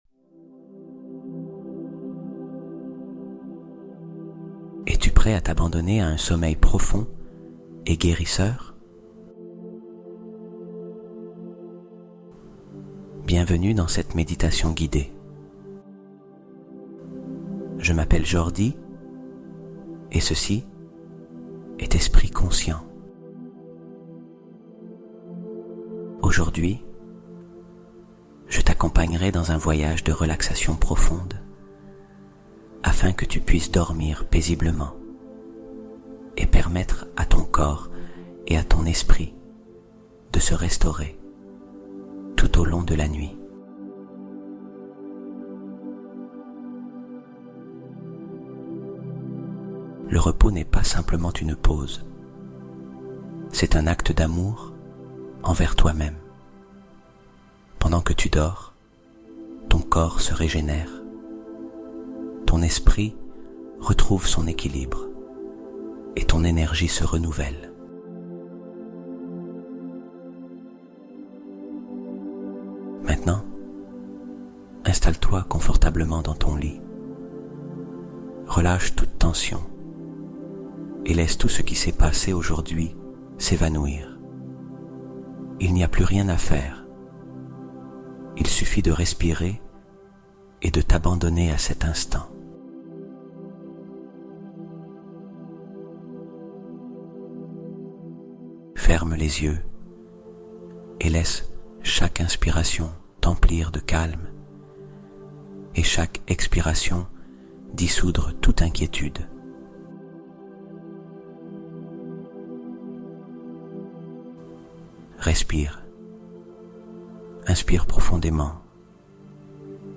Dors et Guéris Toute La Nuit : Méditation Guidée Pour Un Sommeil Profond Qui Répare Tout